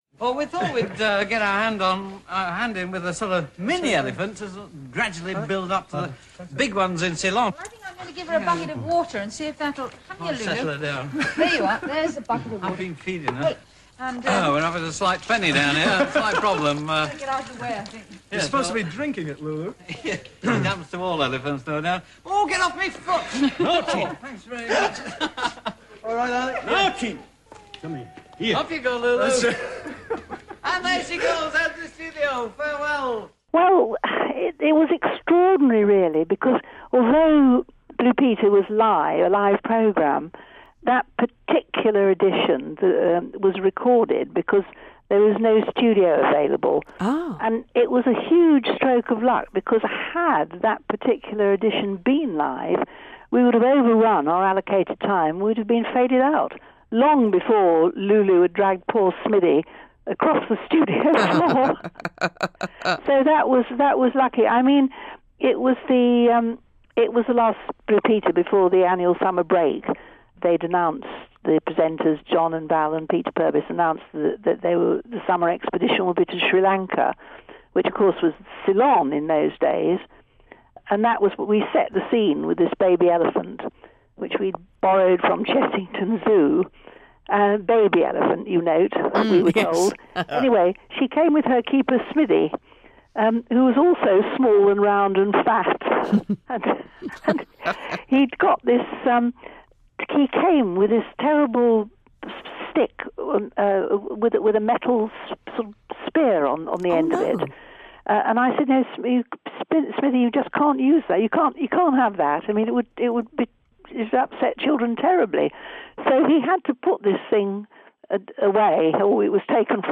Have a listen to Valerie Singleton, John Noakes and Peter Purves attempting to present the programme whilst competing with Lulu's antics.